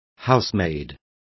Complete with pronunciation of the translation of housemaid.